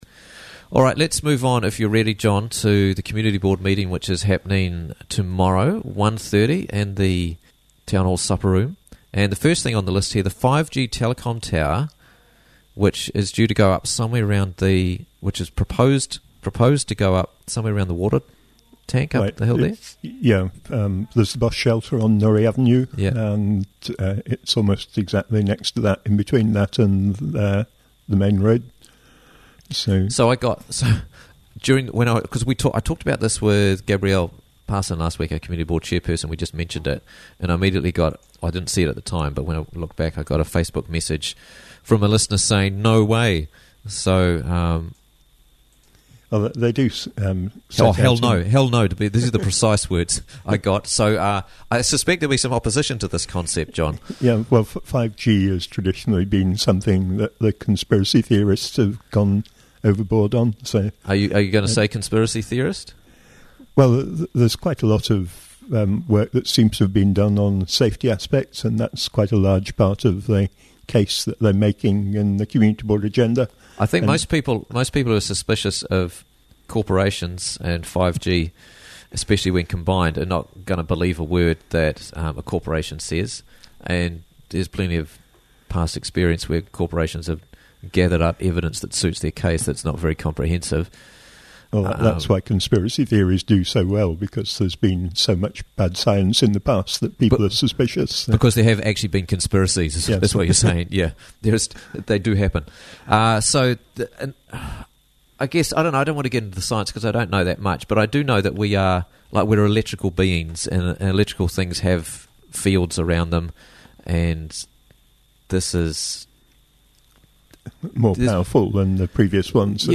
Raglan Community Board Meeting Wednesday - Interviews from the Raglan Morning Show